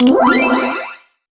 magic_general_item_collect_05.wav